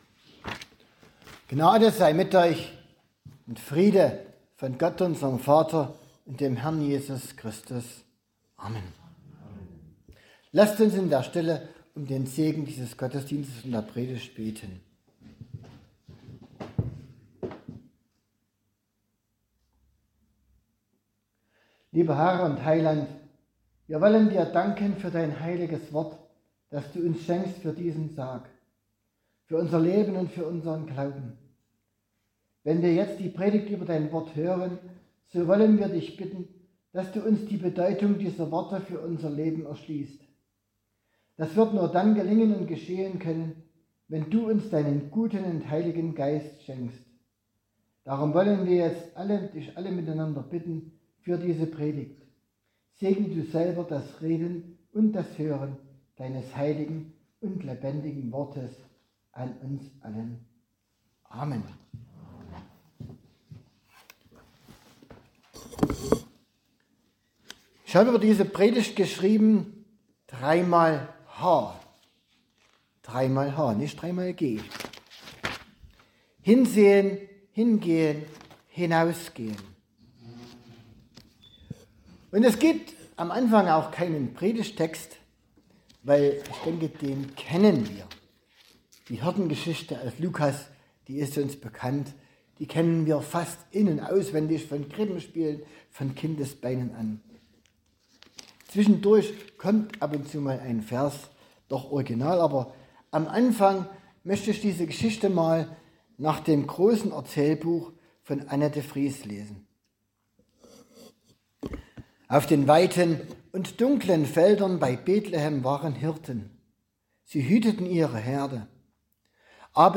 8-20 Gottesdienstart: Posaunengottesdienst Die Hirten von Betlehem erfahren als erste von der Geburt des Heilands.